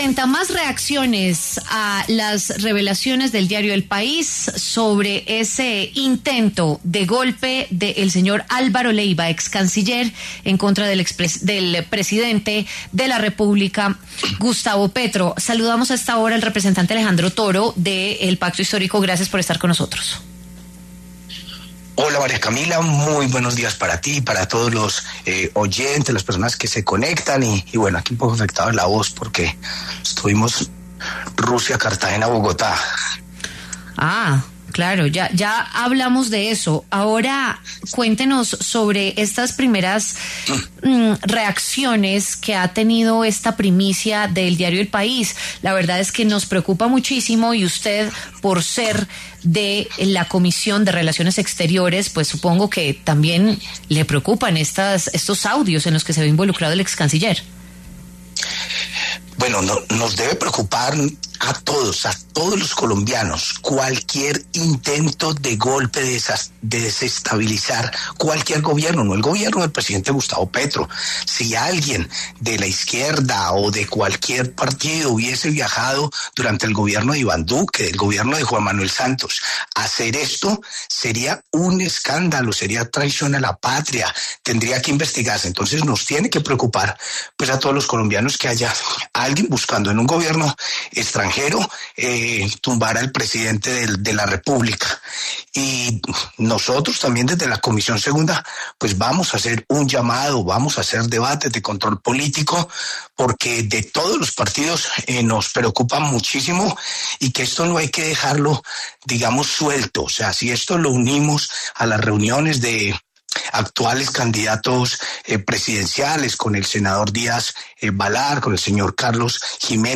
El representante a la Cámara por parte del Pacto Histórico, Alejandro Toro, pasó por los micrófonos de La W para hablar sobre el supuesto plan del excanciller Álvaro Leyva contra el presidente Gustavo Petro.